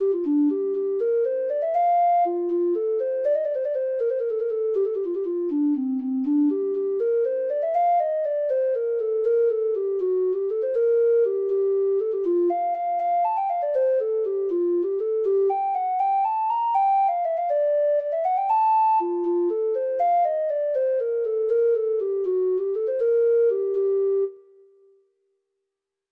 Traditional Sheet Music